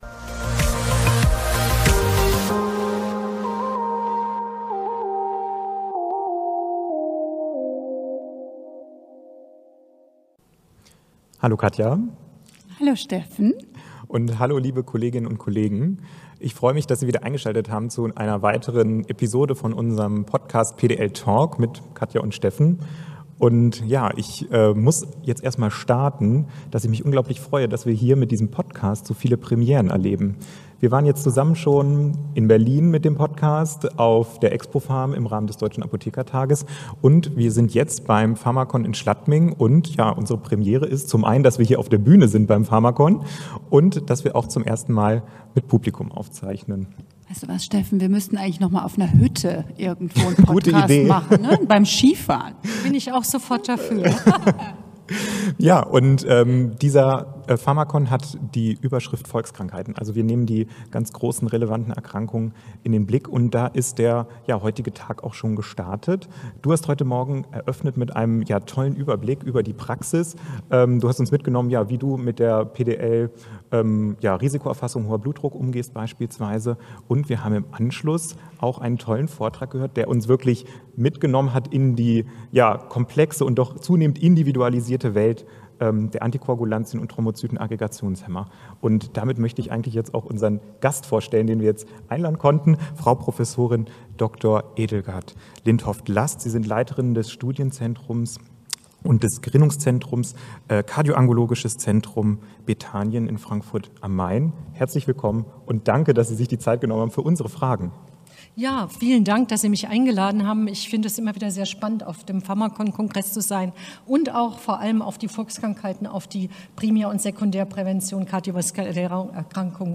Live vom pharmacon